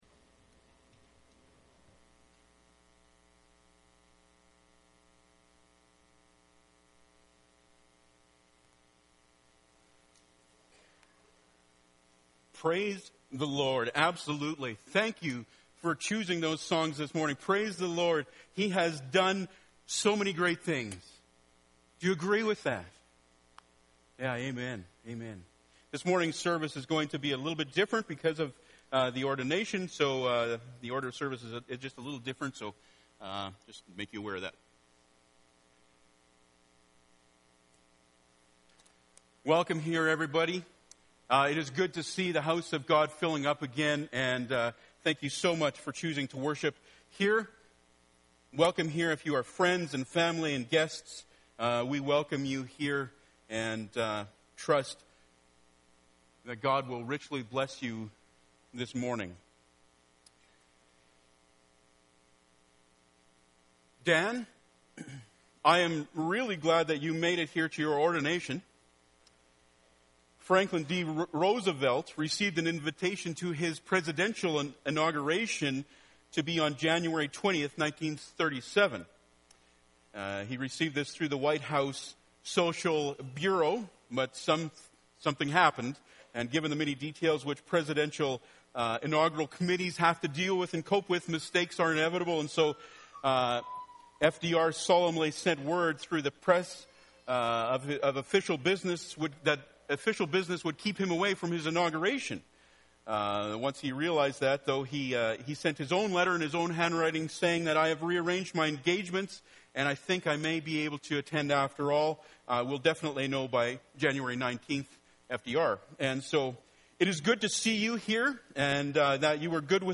Passage: 1 Peter 5:1-4 Service Type: Sunday Morning